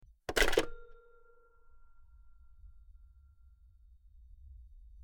Rotary Phone 03
Rotary_phone_03.mp3